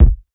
KICK_THE_BATTLE.wav